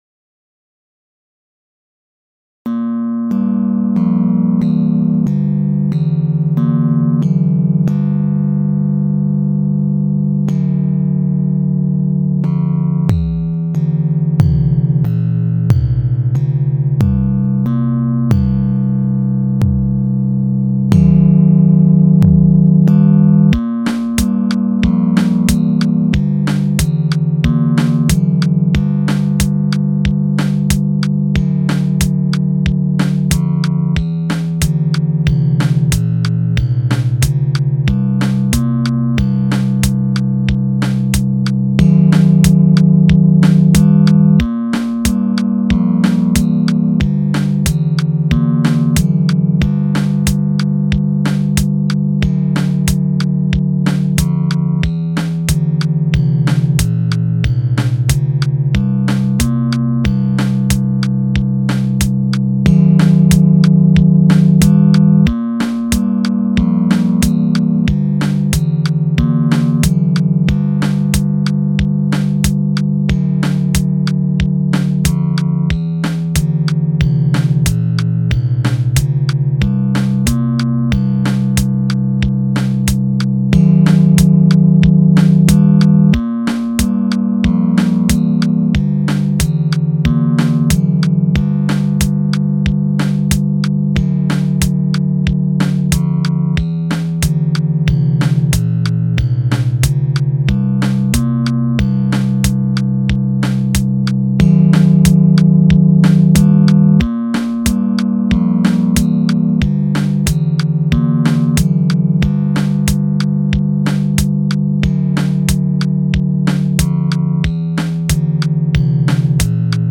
Music for videogames. Dark. For horror or suspense.
The same thing repeats over the whole song because it was intended to be used for a retro game where the same beat is repeated in the while level (like mario)
horror suspense synth lmms .wav License(s